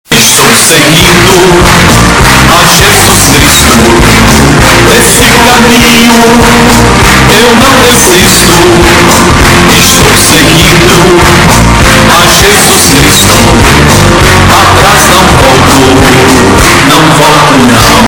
Sound Buttons: Sound Buttons View : Show Da Fé Estourado